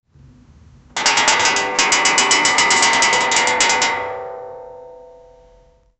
Collapse Sound Effects - Free AI Generator & Downloads
falling-metal-hangar-tspede2d.wav